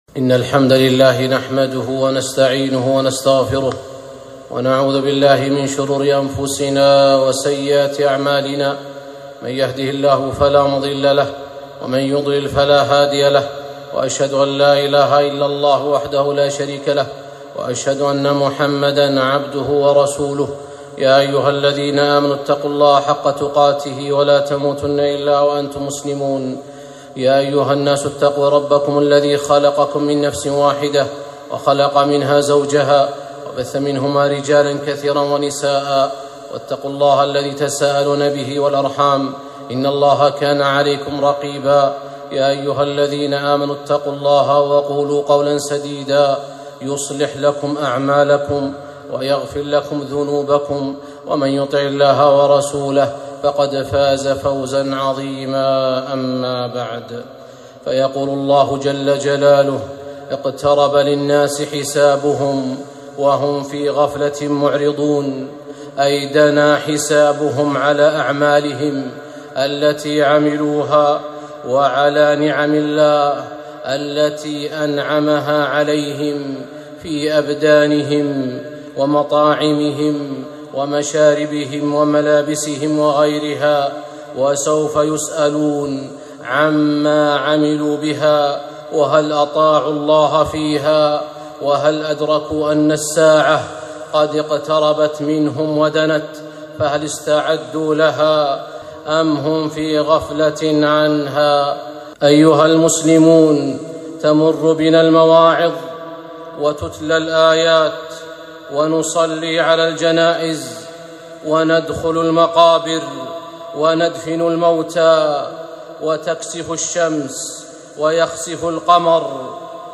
خطبة - أين المعتبرون؟